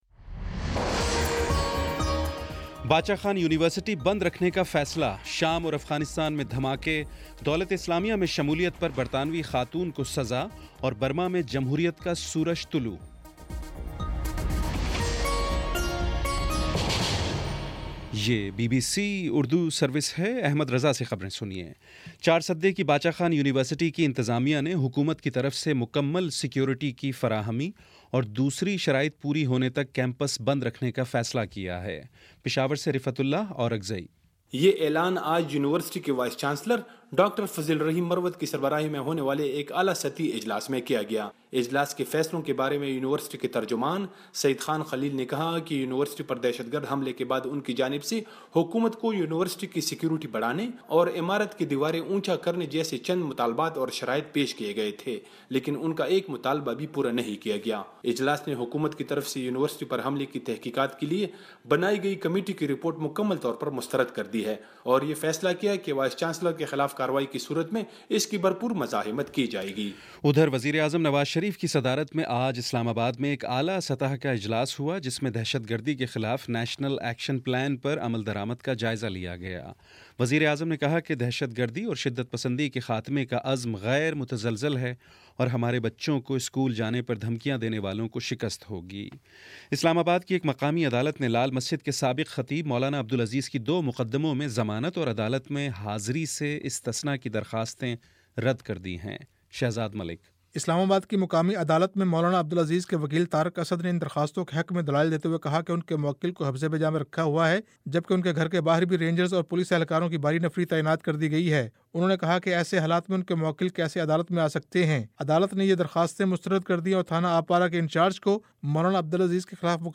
فروری 01 : شام چھ بجے کا نیوز بُلیٹن